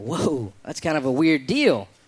Tags: aziz ansari aziz ansari comedian